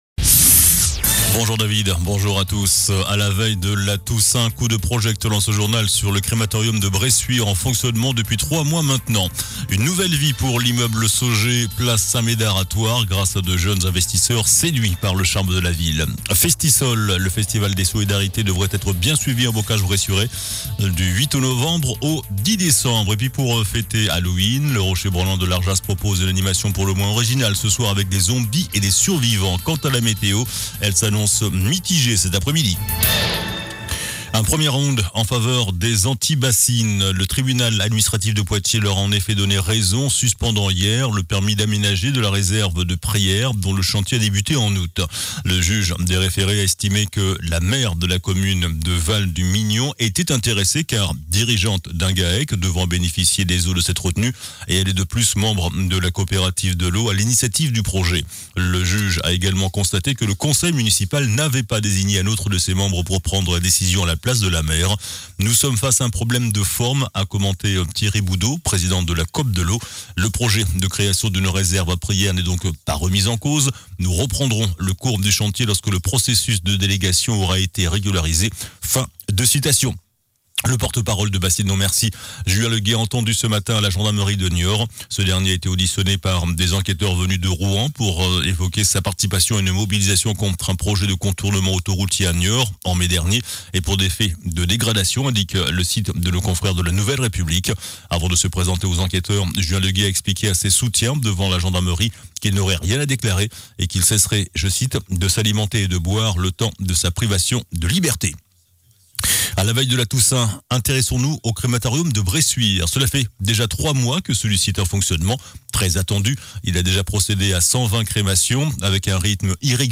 JOURNAL DU MARDI 31 OCTOBRE ( MIDI )